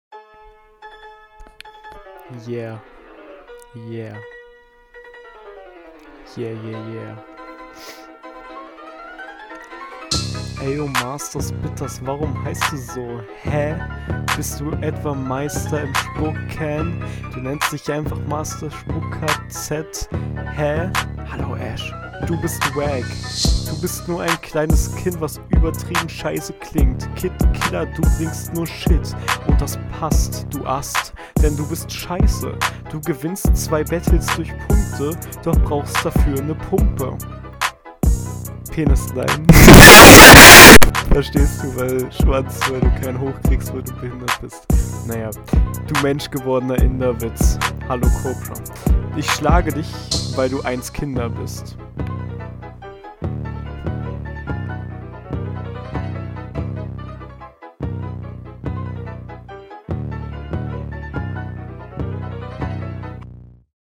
Richtig geiler Beat